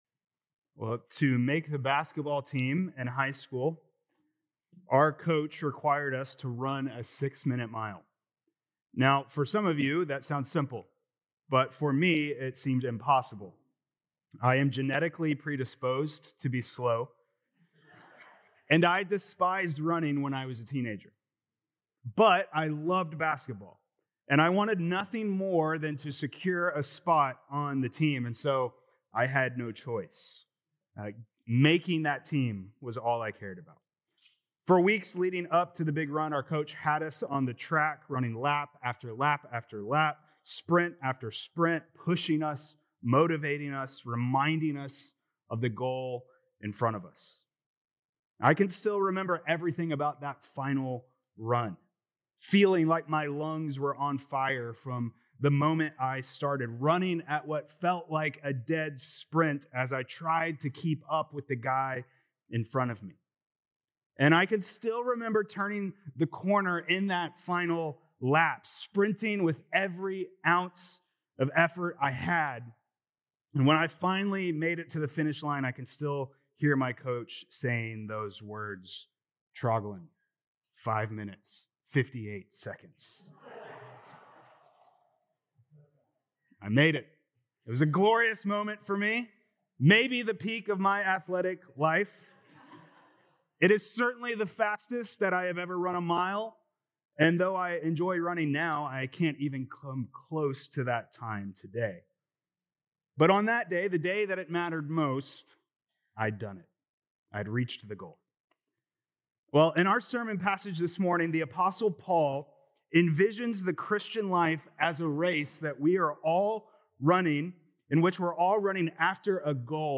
Sept 21st Sermon